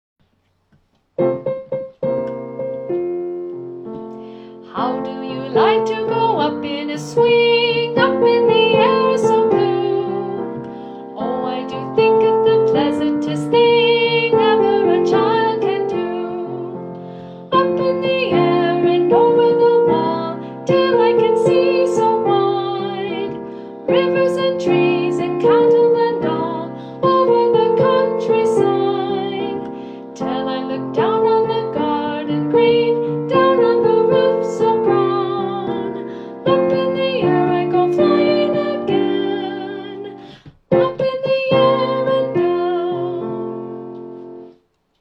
Vocal solo and piano
The-Swing-Live-Audio.mp3